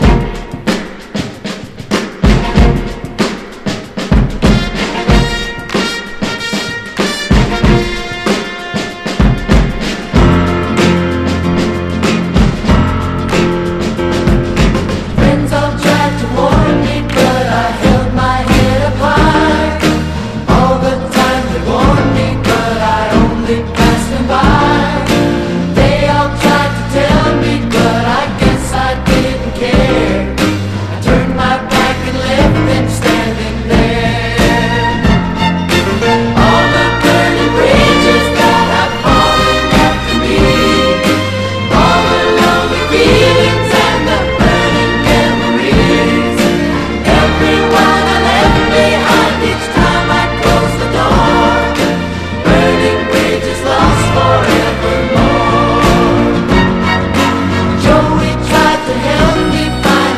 ファンク/レアグルーヴ・サントラ！
重層なストリングス混じりのありそうでなかなかないドラム・ブレイク始まり
ファズギターが炸裂、口笛テーマのへヴィー級ファンク